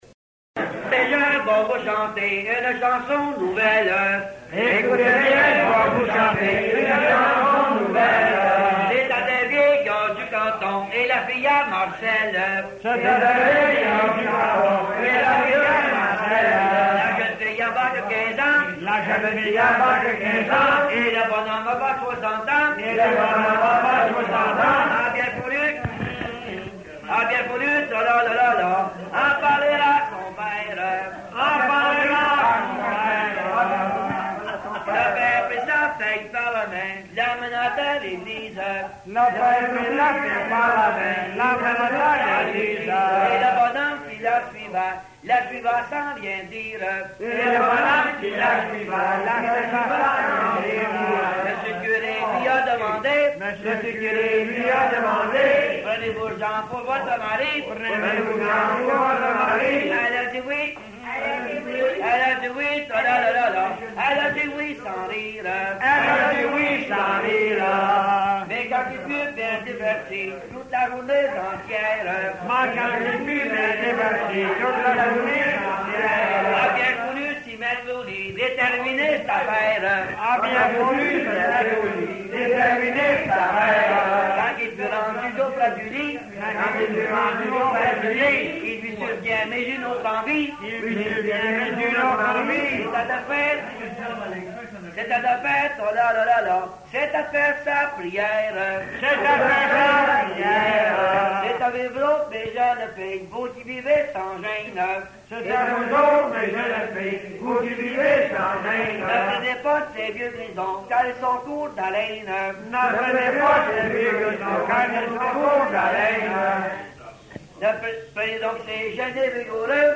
Folk Songs, French--New England